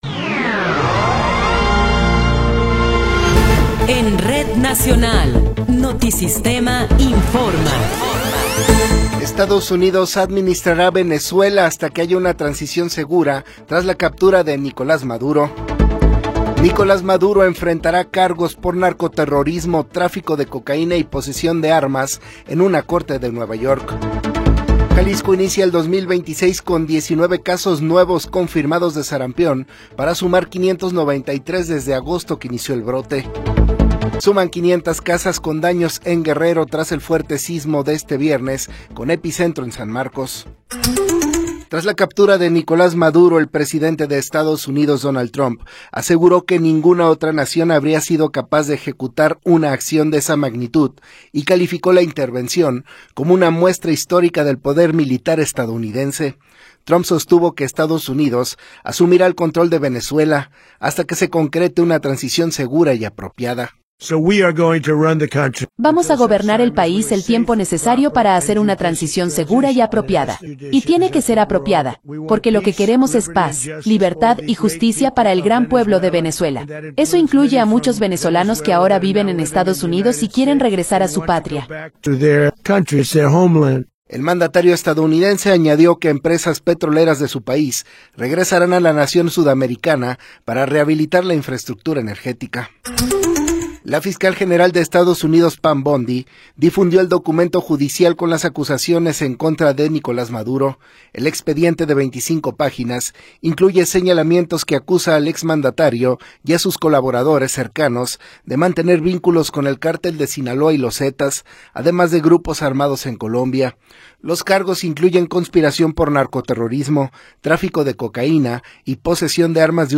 Noticiero 20 hrs. – 3 de Enero de 2026
Resumen informativo Notisistema, la mejor y más completa información cada hora en la hora.